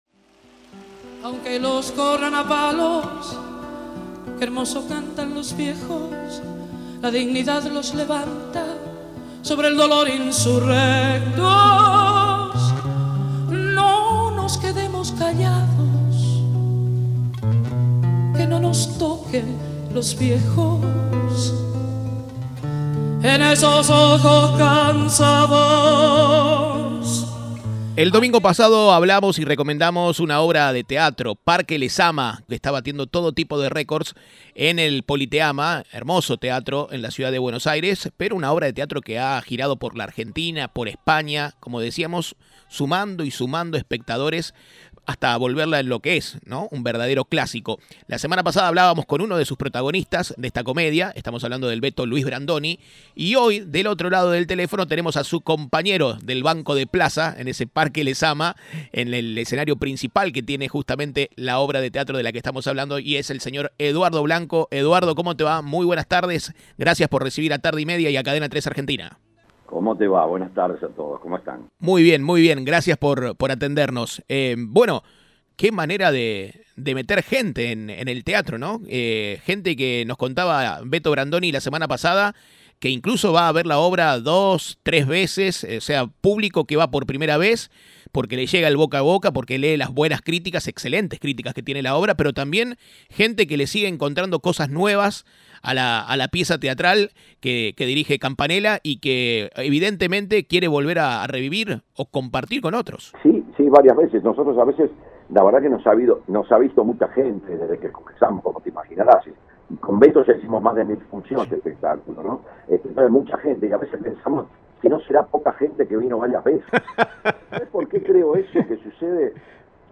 Entrevista de Tarde y media.